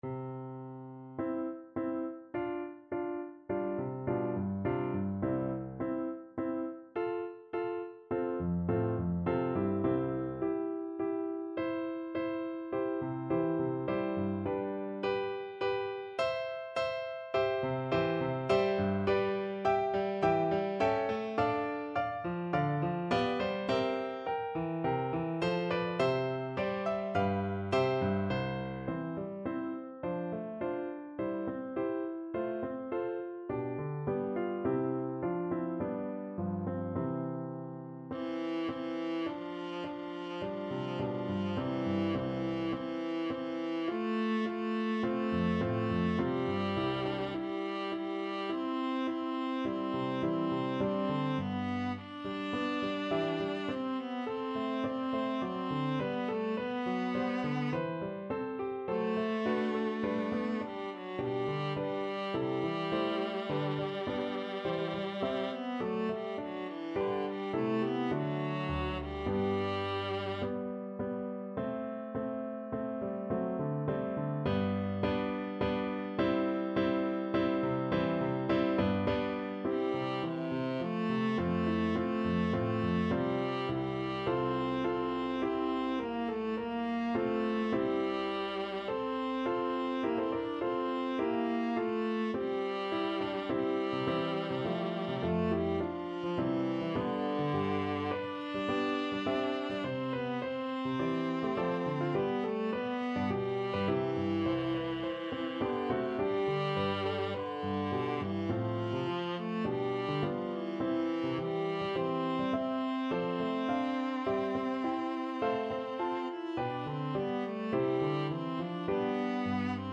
Free Sheet music for Viola
Viola
C major (Sounding Pitch) (View more C major Music for Viola )
4/4 (View more 4/4 Music)
Larghetto (=c.52)
C4-E5